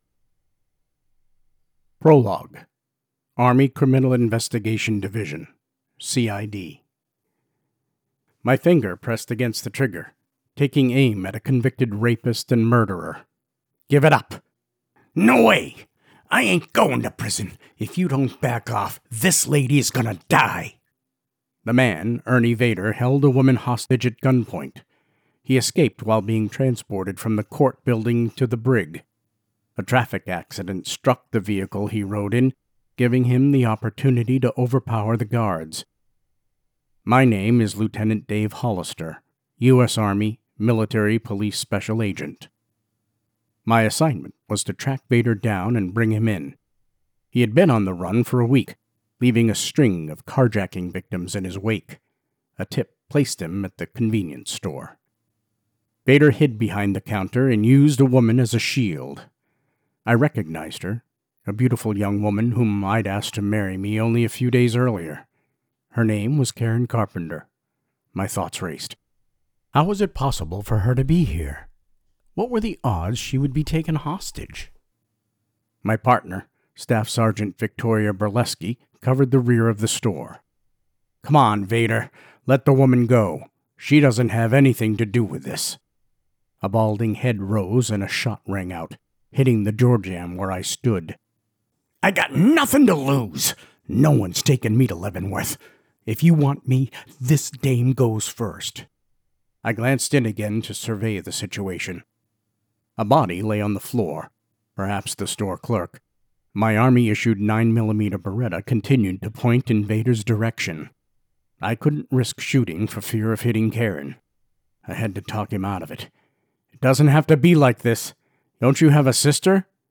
Audiobook: Finding Justice: Dave Hollister, Private Investigator